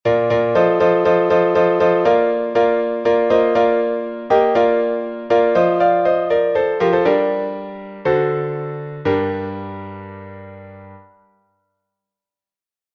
Глас 8